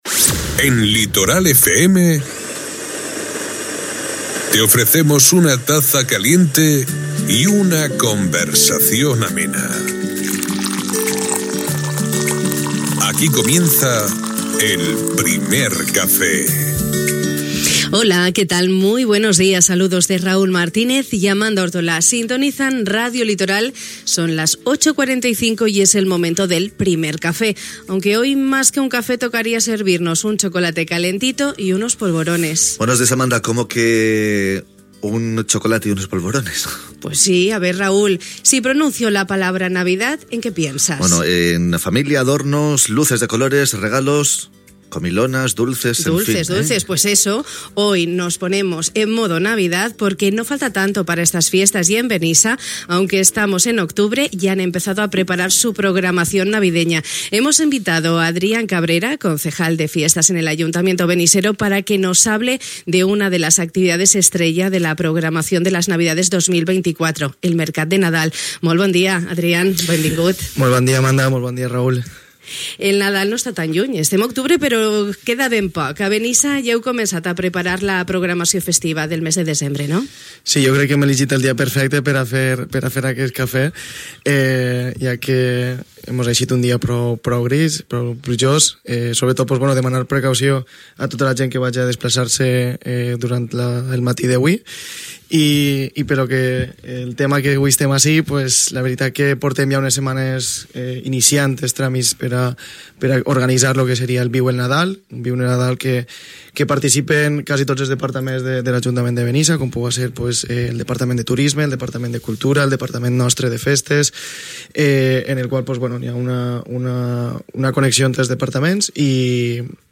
Hui al Primer Café de Ràdio Litoral, ens hem impregnat d’esperit nadalenc, i buscant la màgia que envolta aquestes dates hem conversat amb el regidor de Festes, Adrián Cabrera, per a conèixer els preparatius d’una nova edició del Mercat de Nadal.